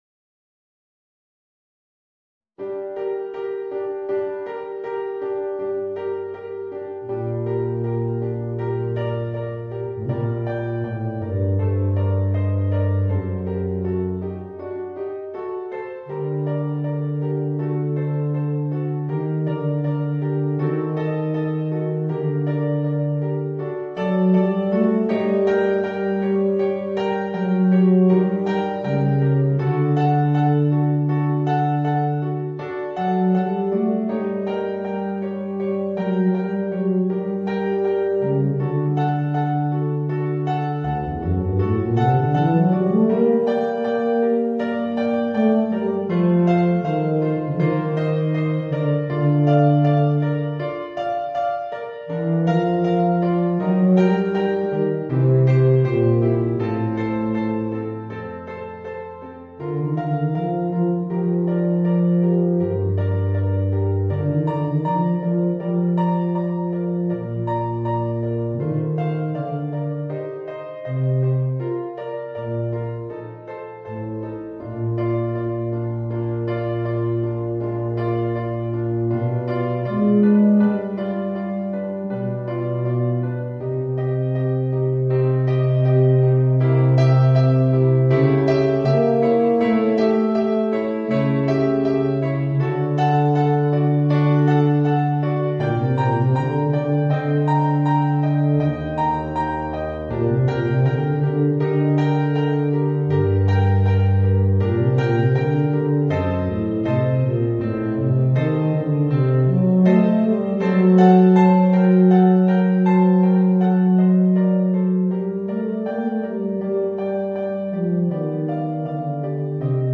Voicing: Eb Bass and Piano